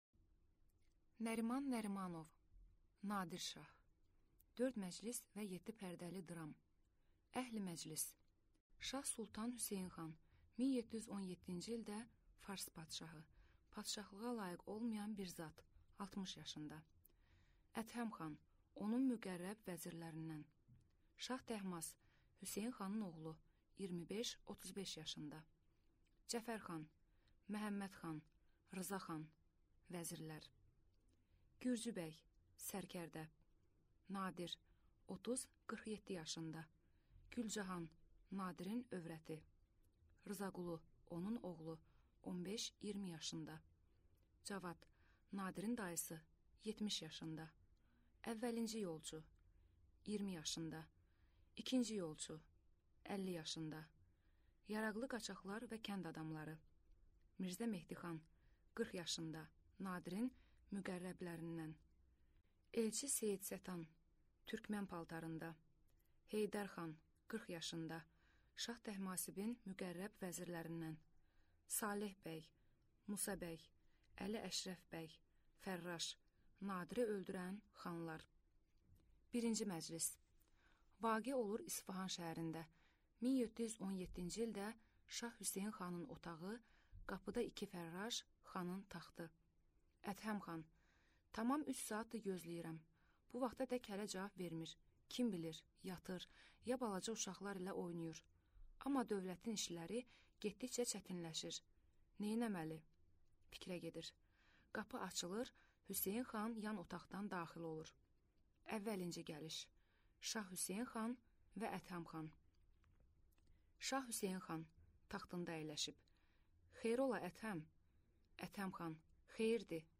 Аудиокнига Nadir şah | Библиотека аудиокниг
Прослушать и бесплатно скачать фрагмент аудиокниги